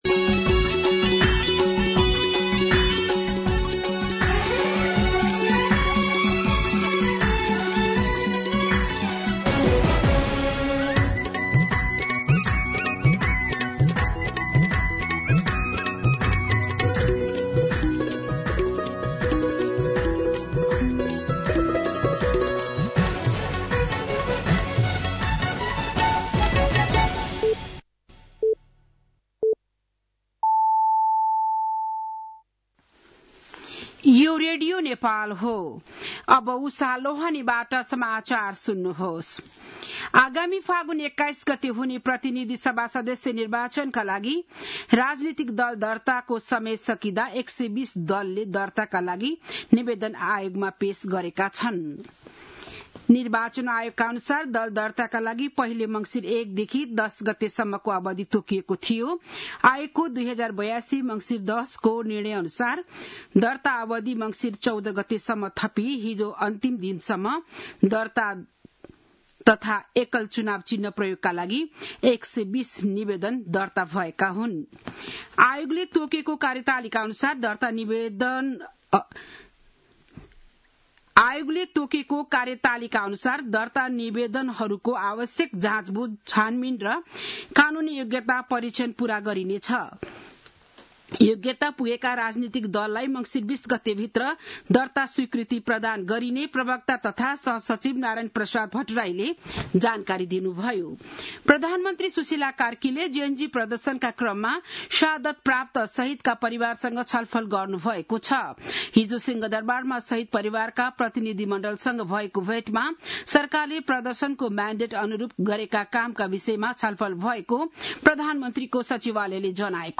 बिहान ११ बजेको नेपाली समाचार : १५ मंसिर , २०८२